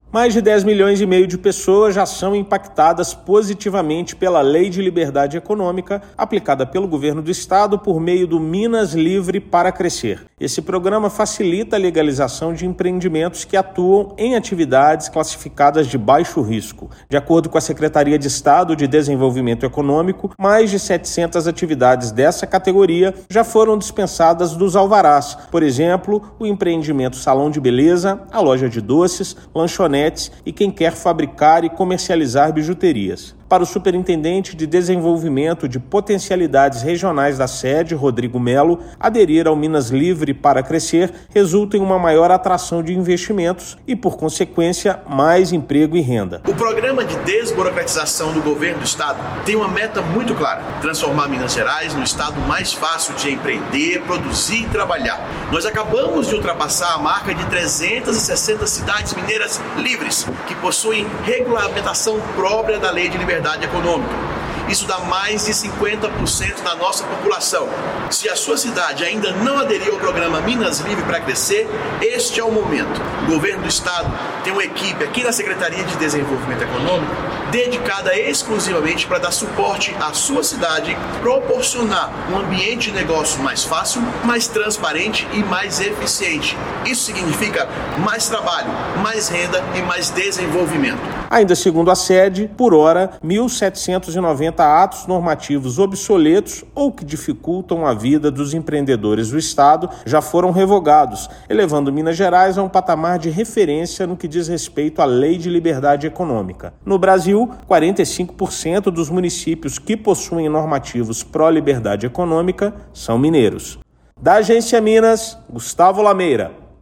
Municípios avançam na atração de investimentos e geração de empregos e renda. Ouça matéria de rádio.